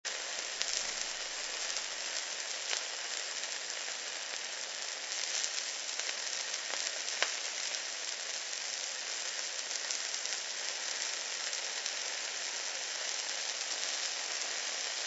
Sizzle
Note that the samples are mp3 (lower quality than the CD) to keep download times short.
The steak is on the grill, and there is no other sound. Just the sizzle and pop as clouds of steam, a little smoke, and layers of that fabulous aroma curl lazily through the air around you.
off-white-noise-sizzle-15s.mp3